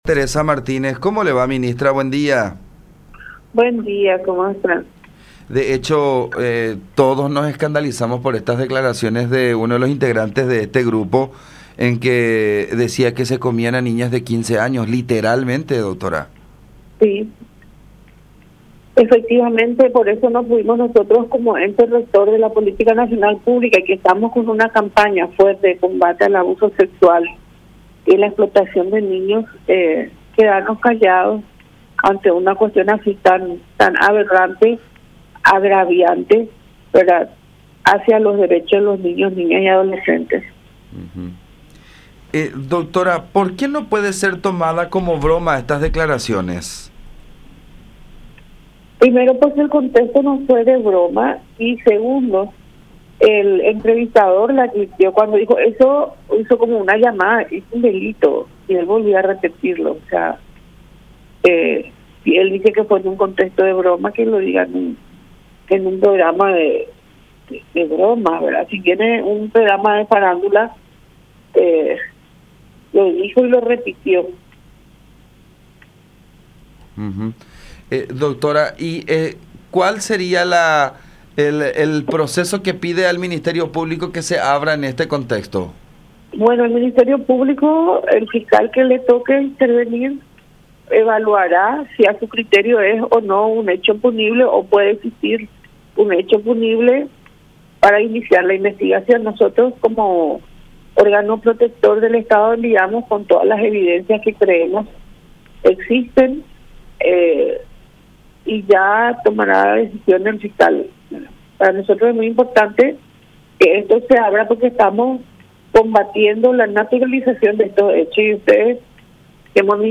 “No puede ser considerado como una broma. Lo dijo y lo repitió. Es muy agraviante”, repudió Martínez en contacto con La Unión, apuntando que las declaraciones se contraponen totalmente con la política del MINNA con respecto a la lucha contra la “normalización” de relaciones entre adultos y menores de edad.
04-MINISTRA-TERESA-MARTÍNEZ.mp3